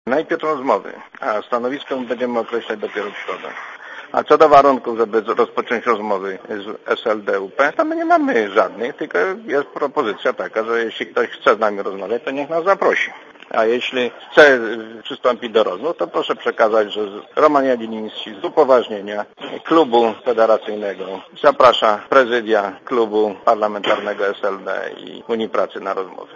Dla radia Zet mówi Roman Jagieliński (102 KB)